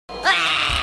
Bubble Bleh Efeito Sonoro: Soundboard Botão